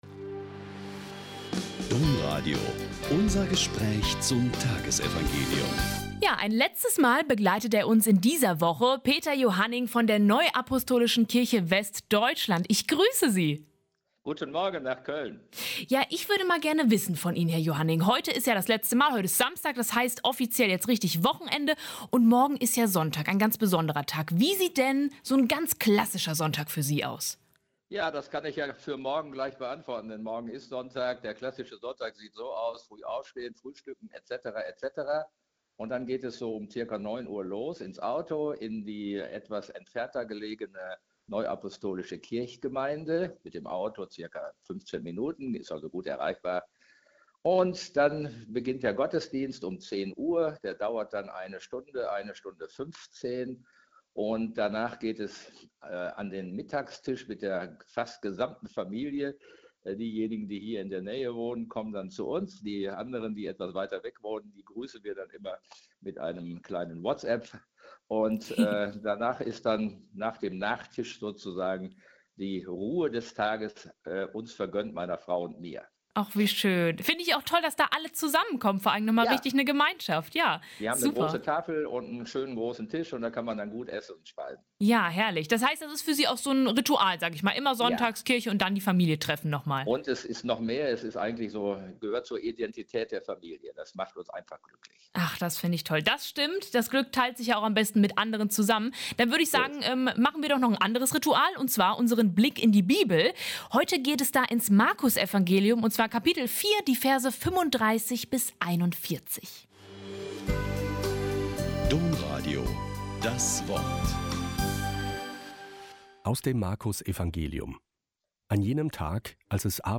Mk 4,35-41 - Gespräch